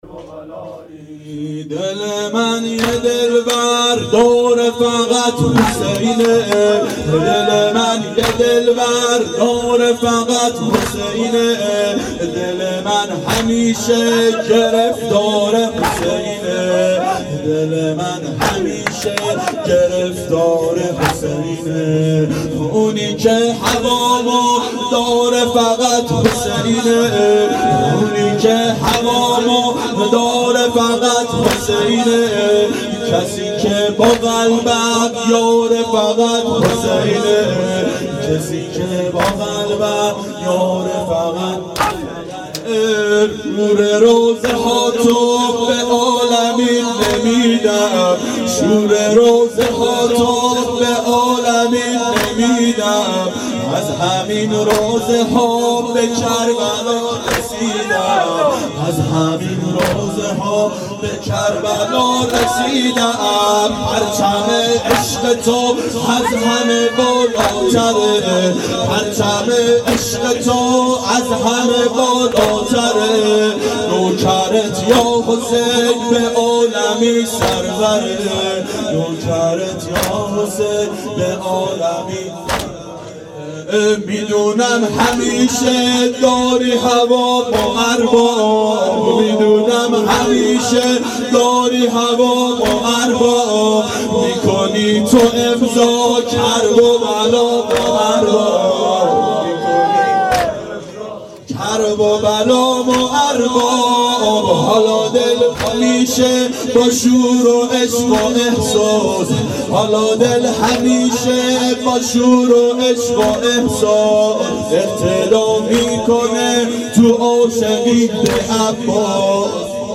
• شب سوم محرم 92 هیأت عاشقان اباالفضل علیه السلام منارجنبان